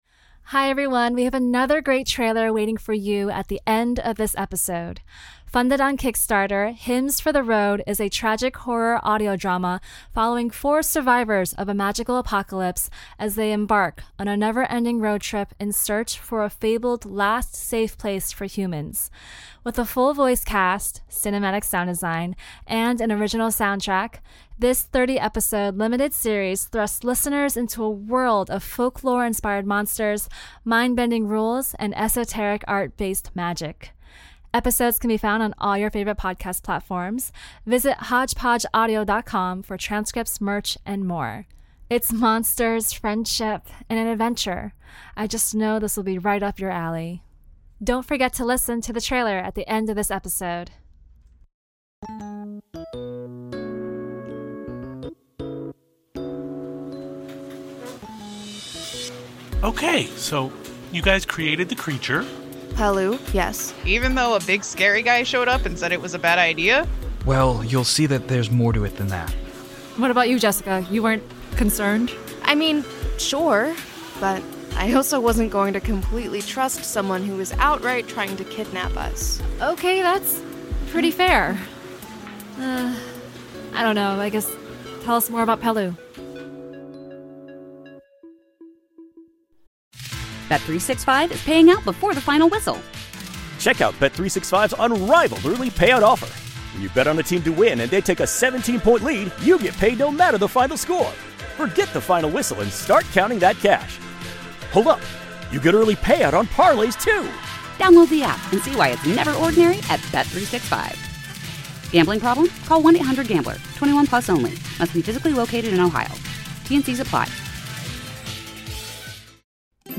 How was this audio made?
Listen to fantastic tales and origins of beasts, monsters, and gods, told in an immersive, full-cast, anime-inspired audio drama.